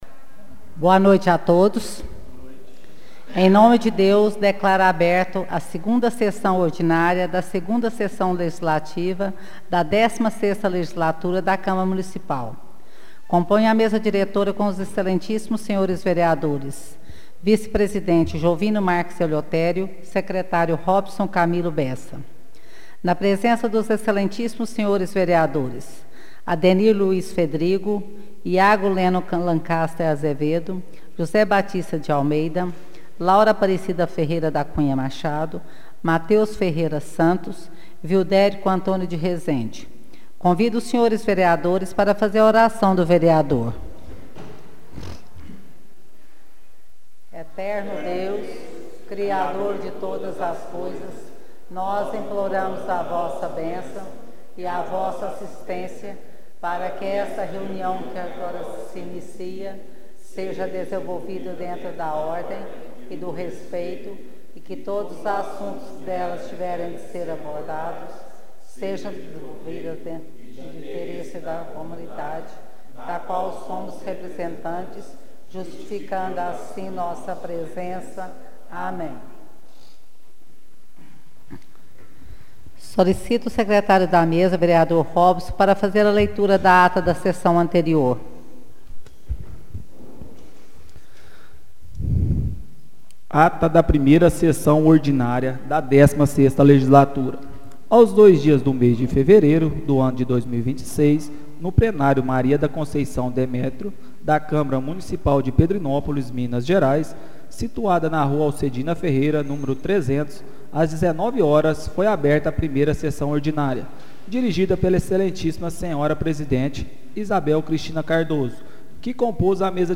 Áudio da 2ª Sessão Ordinária de 2026